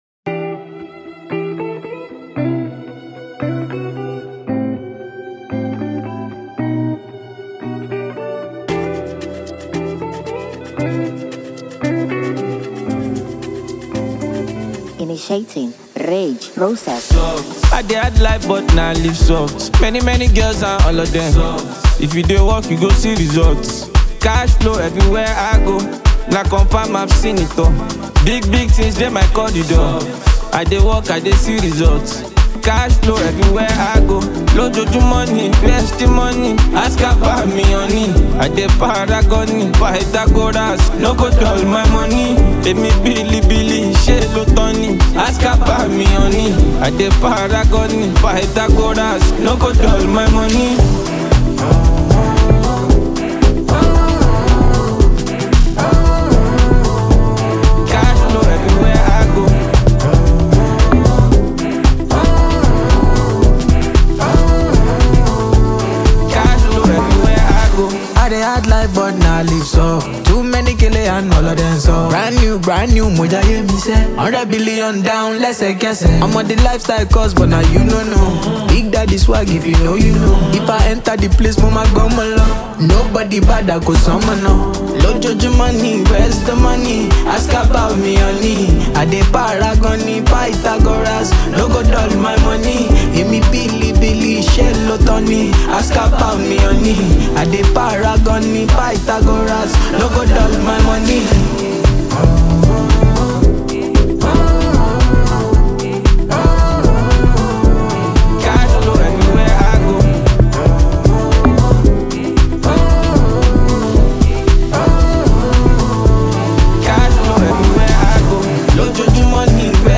smooth vocals, infectious rhythms, and top-notch production
With its addictive melody and polished sound
Afrobeat, Afropop, and contemporary Nigerian music